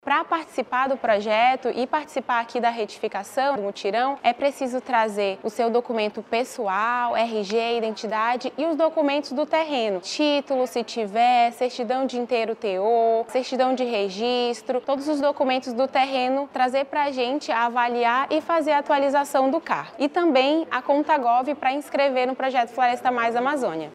SONORA-2-CADASTRO-AGRICULTORES-.mp3